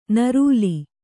♪ narūli